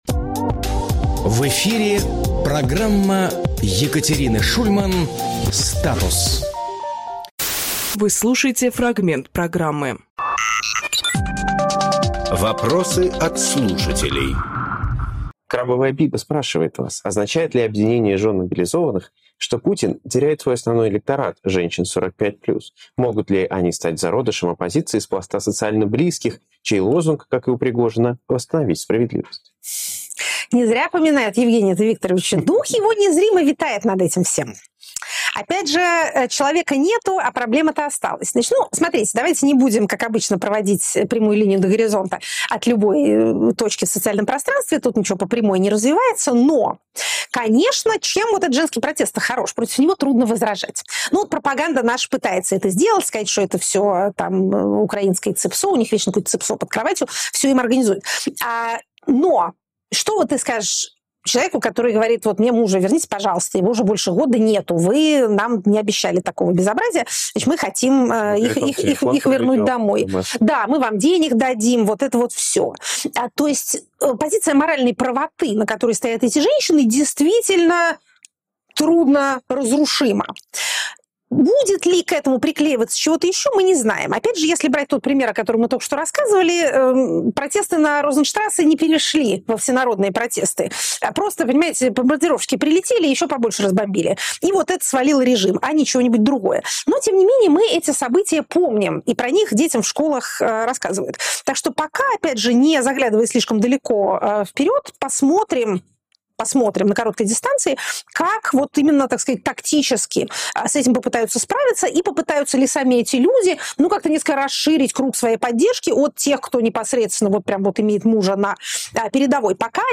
Екатерина Шульманполитолог
Фрагмент эфира от 28.11